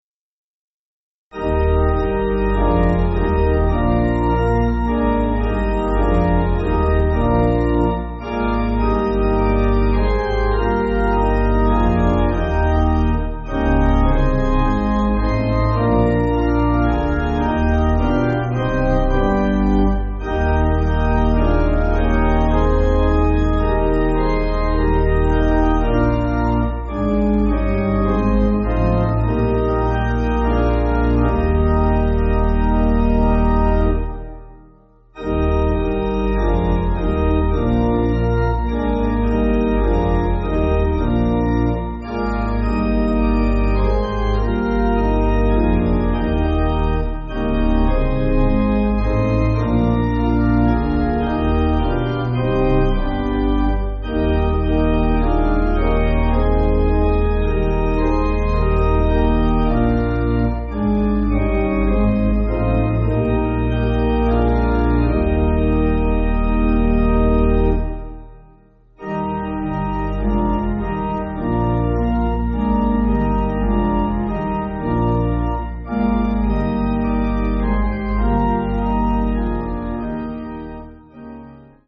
Organ
(CM)   5/Eb